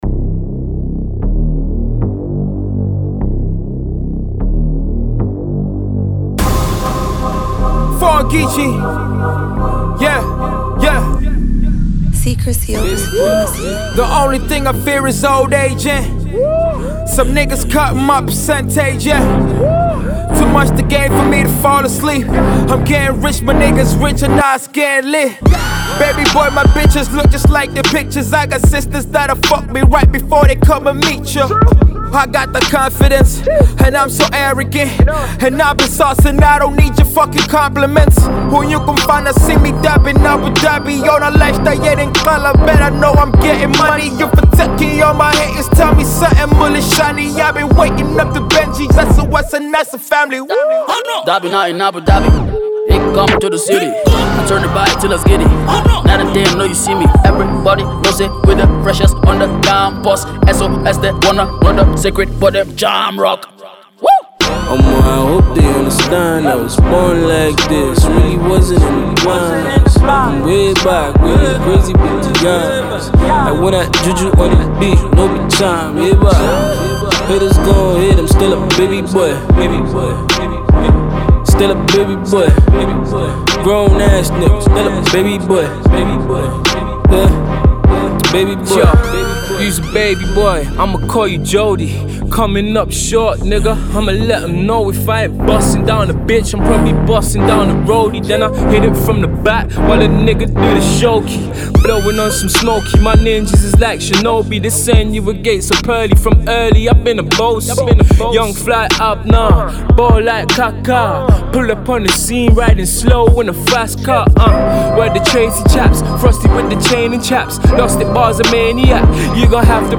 hip-hop group
rap single